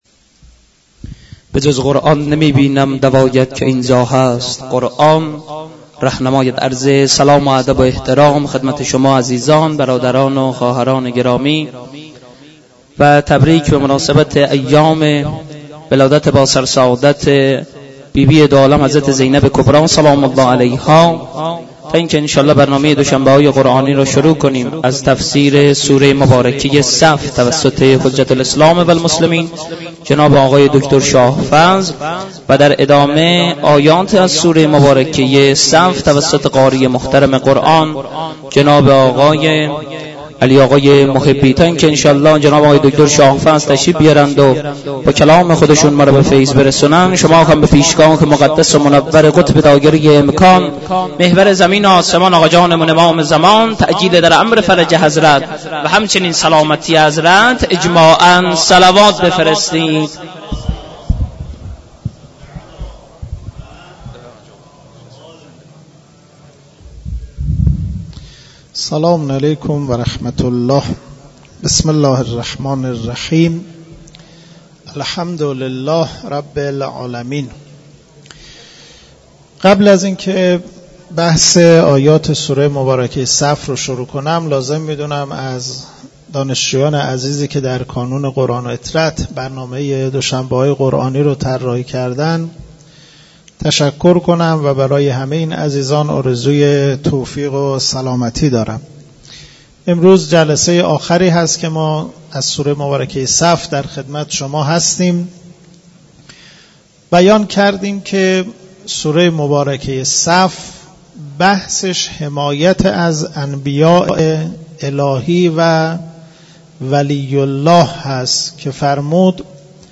مراسم معنوی دوشنبه های قرآنی در مسجد دانشگاه کاشان برگزار شد.